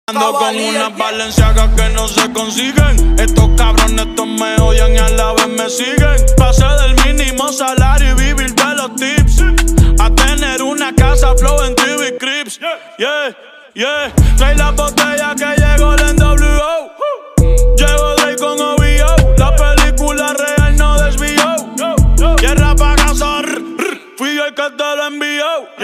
Reguetón